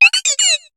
Cri de Dedenne dans Pokémon HOME.